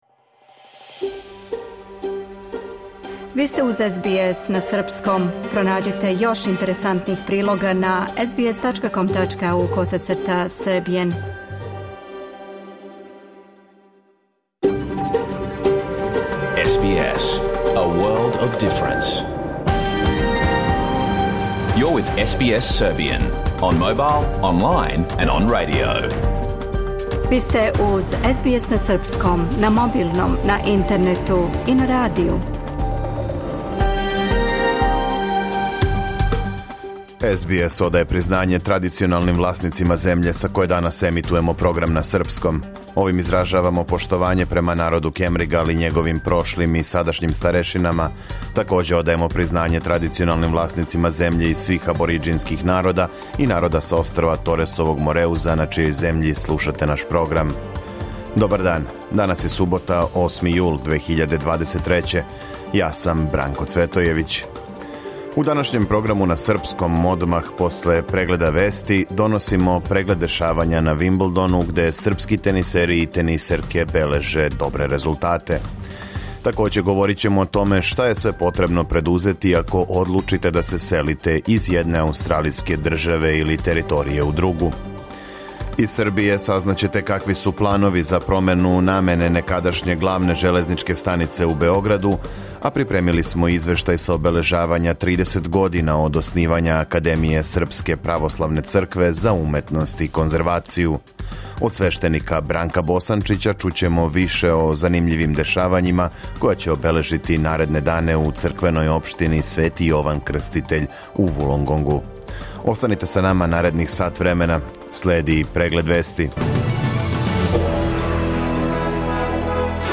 Програм емитован уживо 8. јула 2023. године
Уколико сте пропустили данашњу емисију, можете је послушати у целини као подкаст, без реклама.